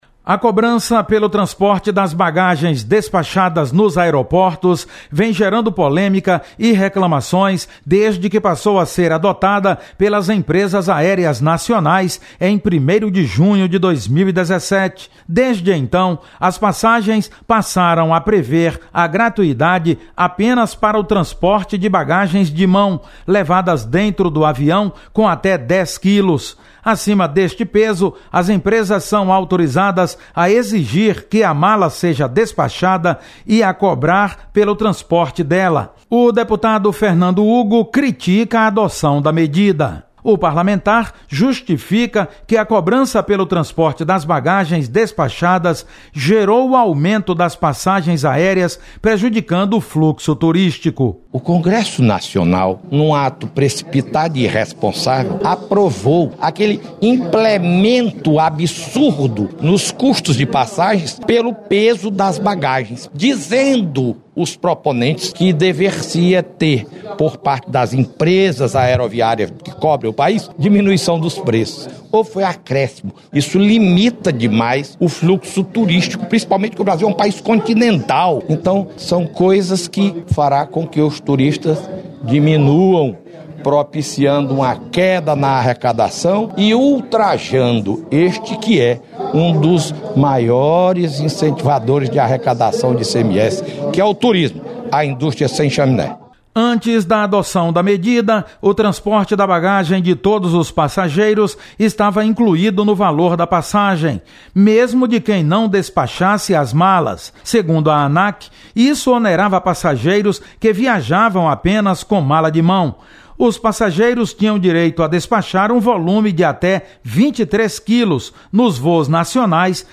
Deputado Fernando Hugo critica cobrança pela bagagem despachada nos aeroportos. Repórter